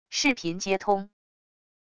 视频接通wav音频